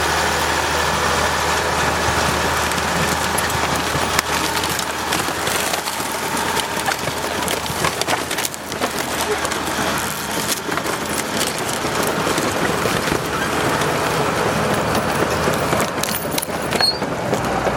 描述：拖拉机，拖拉机的声音
标签： 拖拉机 声音 发动机
声道立体声